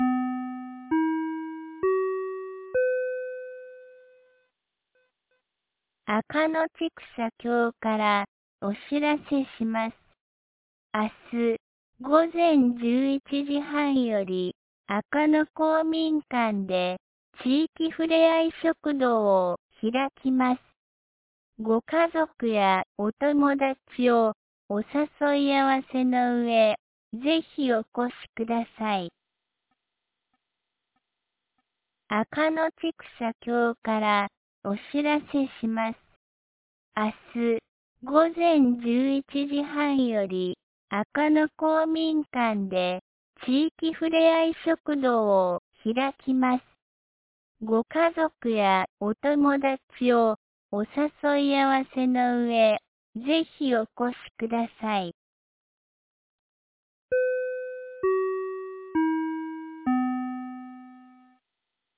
2025年11月08日 17時11分に、安芸市より赤野へ放送がありました。